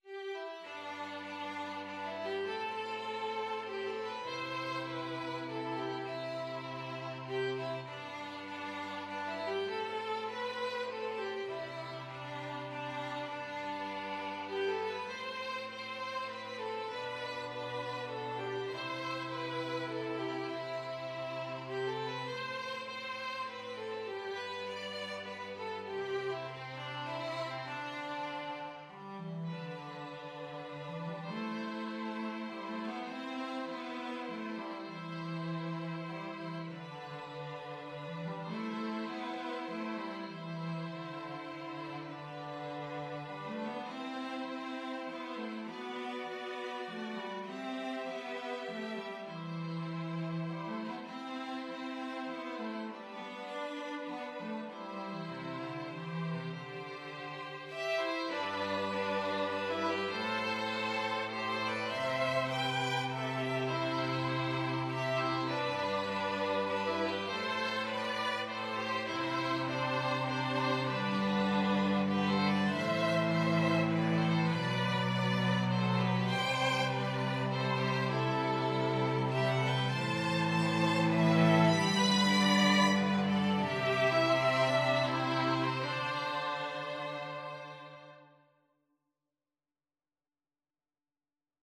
Free Sheet music for 2-Violins-Cello
"Bonny Portmore" is an Irish traditional folk song which laments the demise of Ireland's old oak forests, specifically the Great Oak of Portmore or the Portmore Ornament Tree, which fell in a windstorm in 1760 and was subsequently used for shipbuilding and other purposes.
G major (Sounding Pitch) (View more G major Music for 2-Violins-Cello )
3/4 (View more 3/4 Music)
Moderato, cantabile
Traditional (View more Traditional 2-Violins-Cello Music)